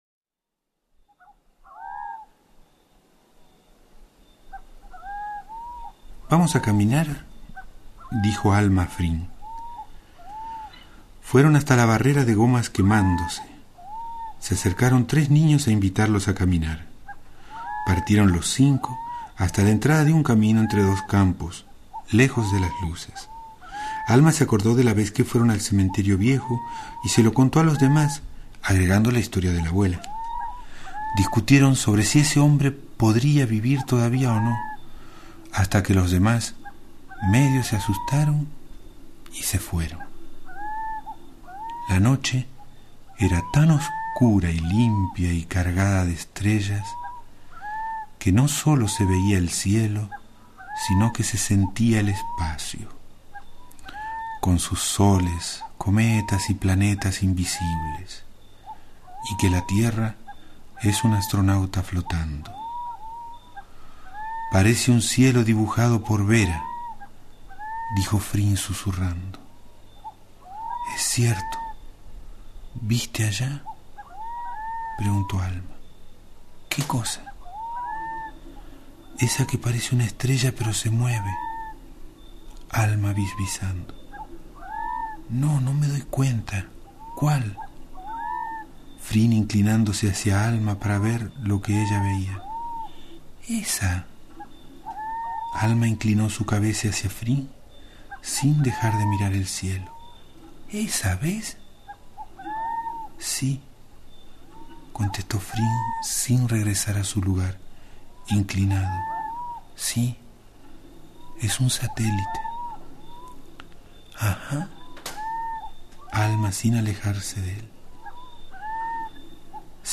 Su lectura en mp3 (del disco “Antología“)